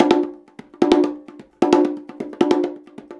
150BONGO 7.wav